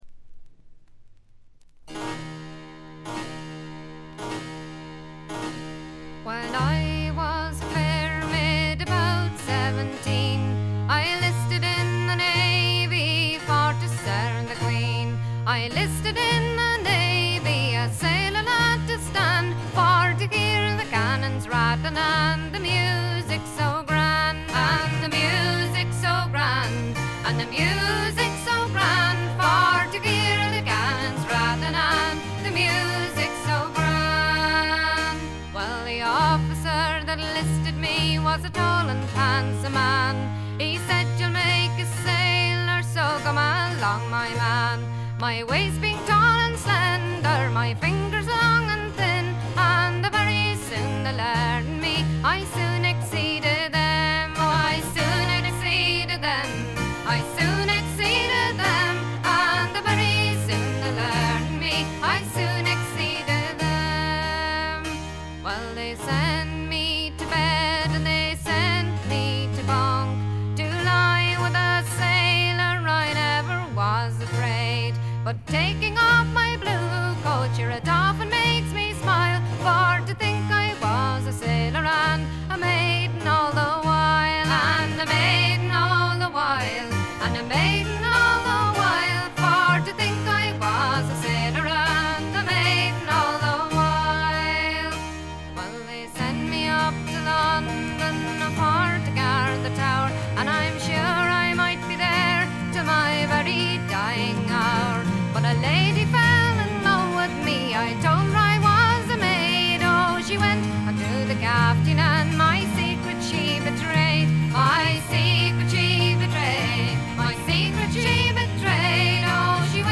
軽微なバックグラウンドノイズ程度。
時に可憐で可愛らしく、時に毅然とした厳しさを見せる表情豊かで味わい深いヴォーカルがまず最高です。
これにパイプやフルートなどがからんでくると、そこはもう寒そうな哀愁漂う別天地。
試聴曲は現品からの取り込み音源です。
vocals, harpsichord, bodhran